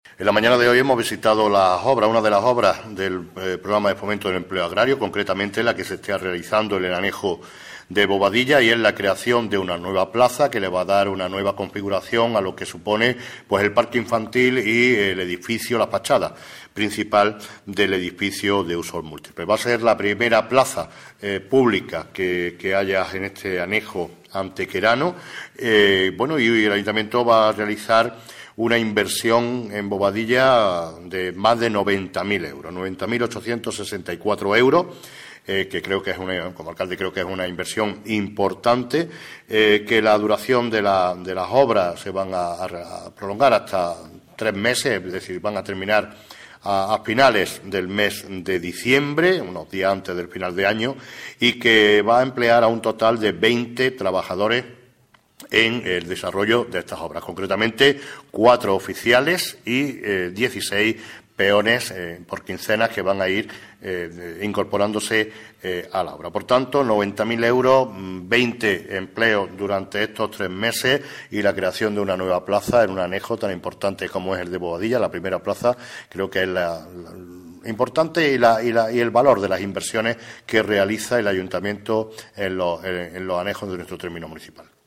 Cortes de voz M. Barón 664.92 kb Formato: mp3